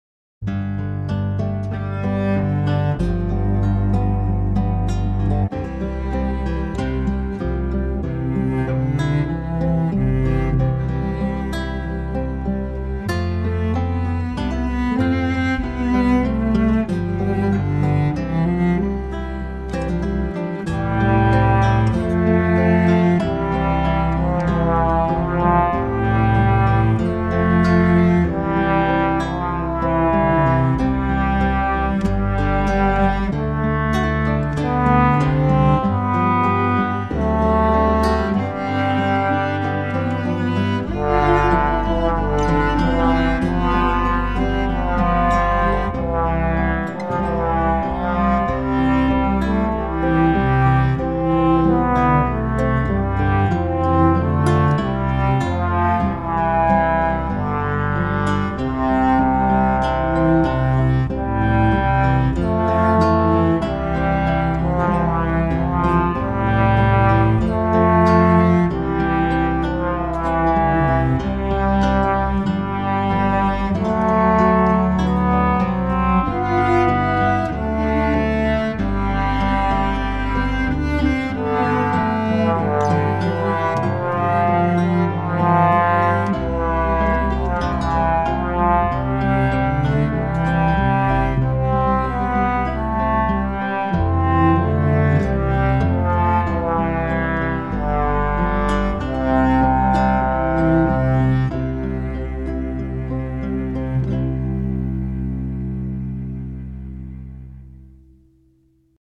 Marian hymn
I’m trying new instruments for melody lines.